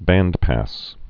(băndpăs)